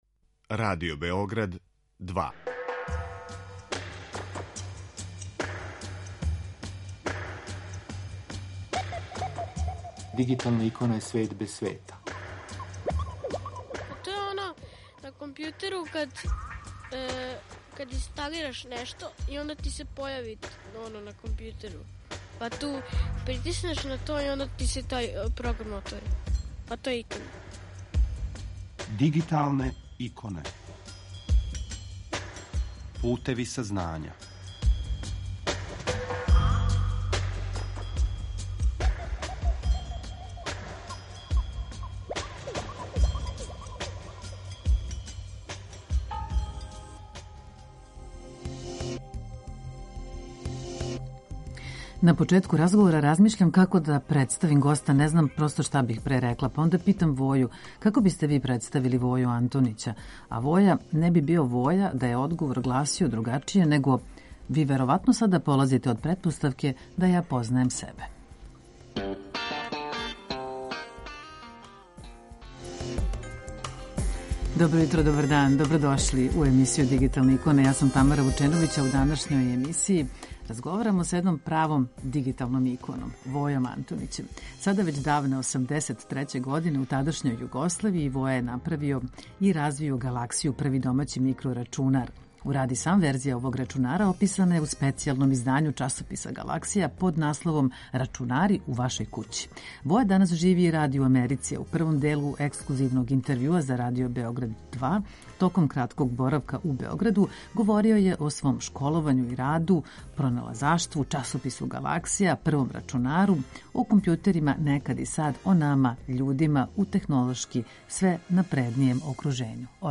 Воја данас живи и ради у Америци, а у првом делу ексклузивног интервјуа за Радио Београд 2, током кратког боравка у Београду, говорио је о свом школовању и раду, проналазаштву, часопису Галаксија и првом рачунару, о компјутерима некад и сад, о нама, људима, у технолошки све напреднијем окружењу...